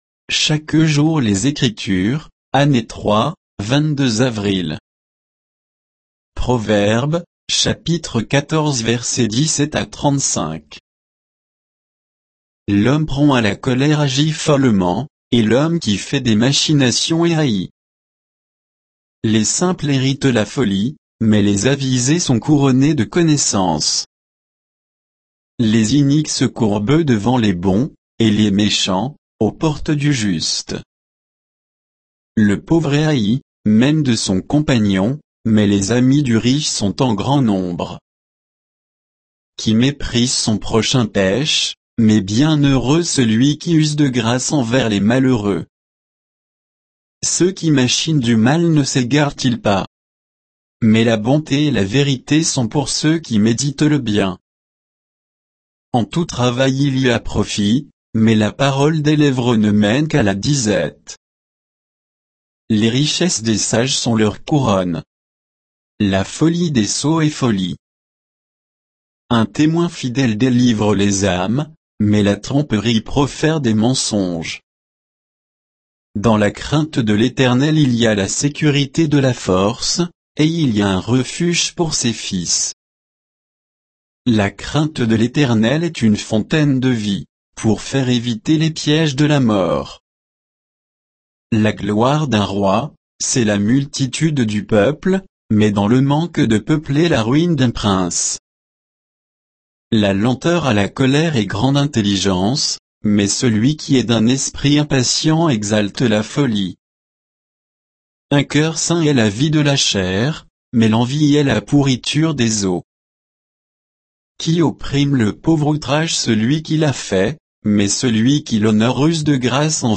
Méditation quoditienne de Chaque jour les Écritures sur Proverbes 14, 17 à 35